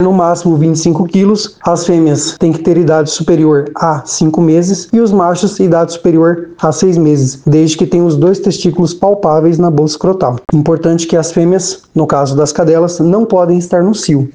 Em entrevista à FM Educativa MS o superintendente de Bem-Estar Animal, Edvaldo Salles, após o encerramento do atendimento, às 13h, os animais passam por avaliação veterinária e recebem microchip.